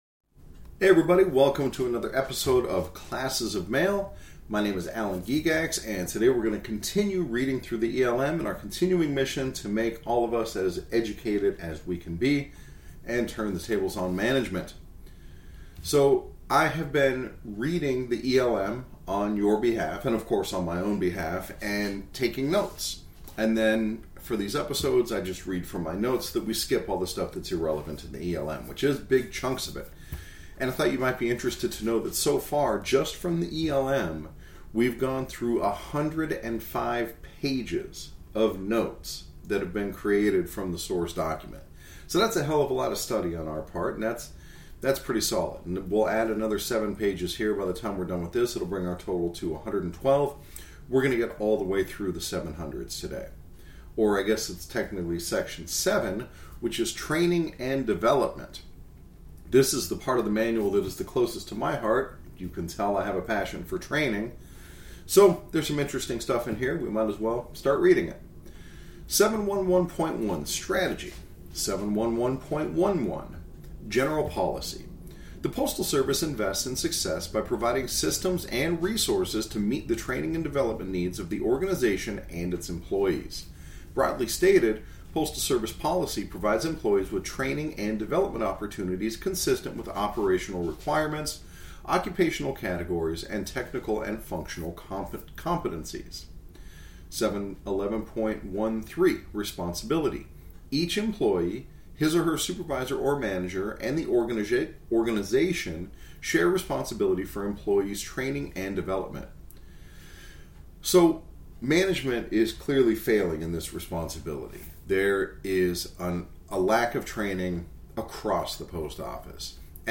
At the end of the episode, we'll learn about our HERO training records, which you should review. Finally, the podcast end with Annie in the background scratching and clawing as she successfully unmakes the guest bed so she can lay down for a nap.